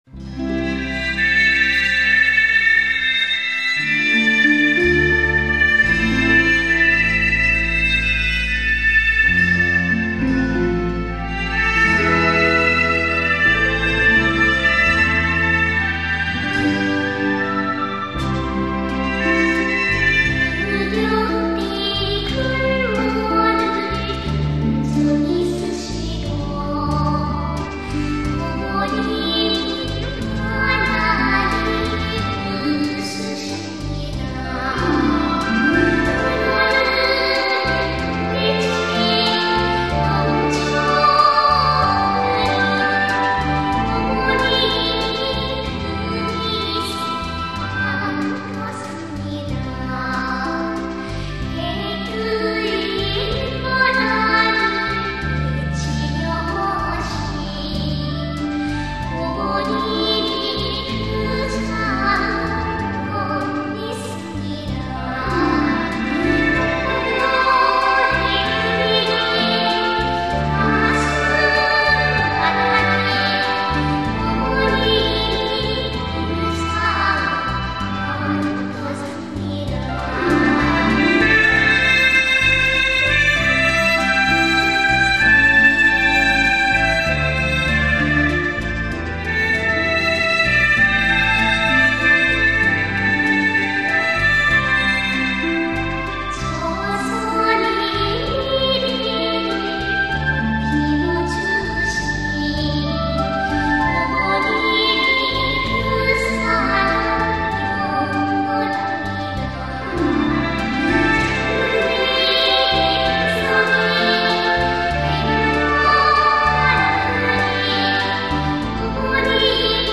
Korean Children's music